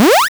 hp-up.wav